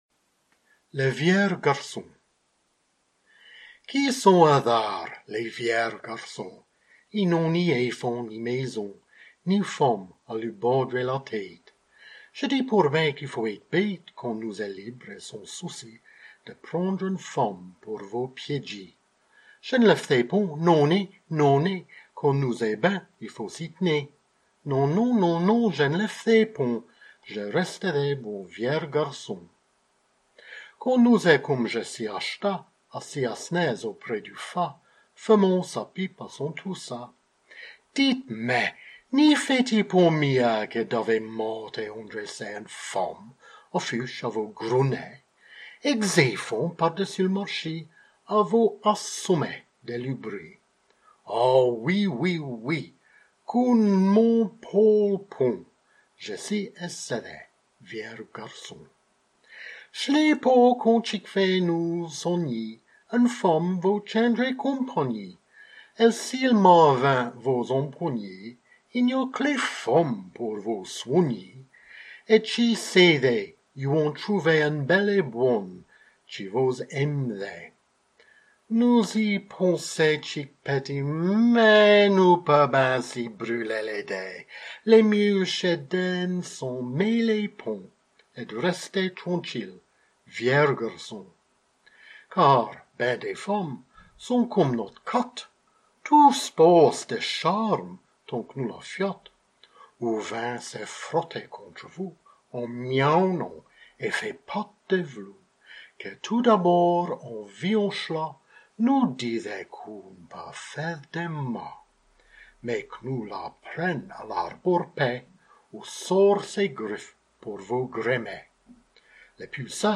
DIT PAR